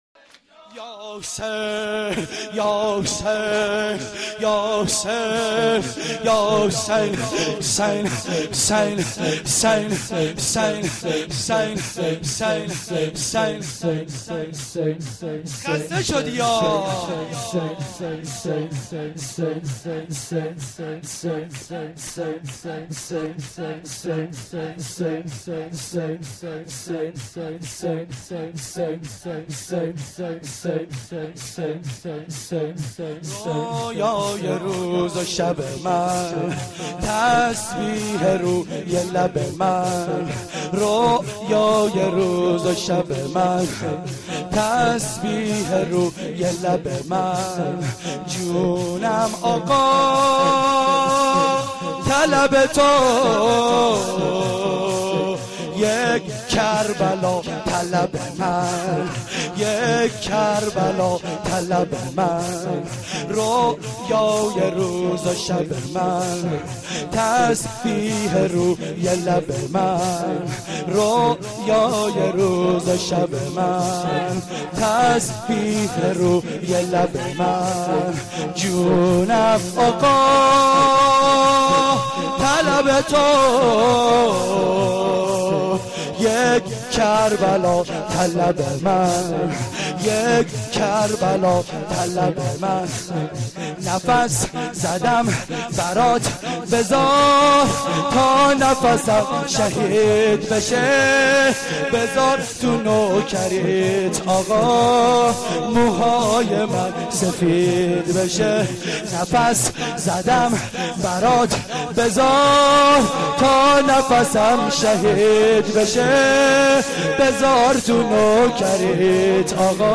محرم92(شب سوم)